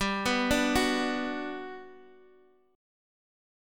Bm/G chord
B-Minor-G-x,x,5,4,3,2-8.m4a